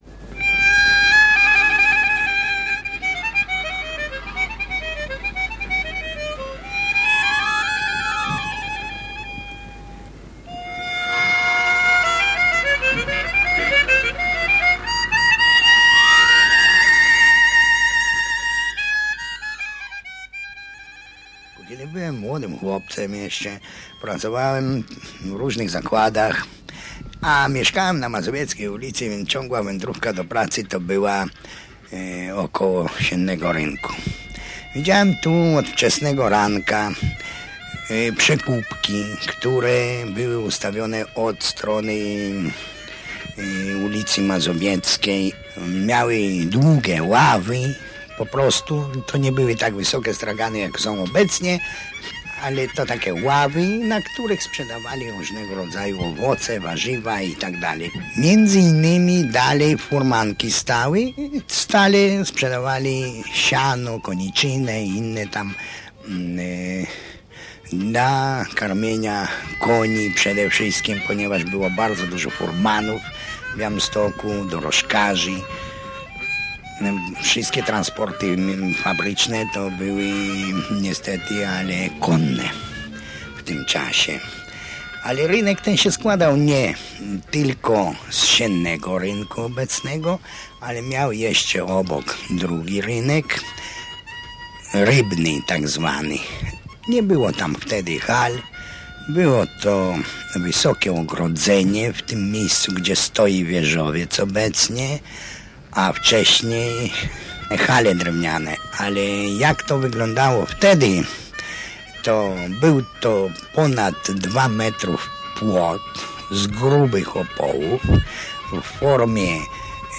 W 1968 roku zlikwidowano w Białymstoku legendarny targ na Siennym Rynku, przeniesiono go na ul. Bema. Jest to pierwszy reportaż dźwiękowy w archiwum naszej rozgłośni.